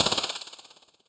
snake.wav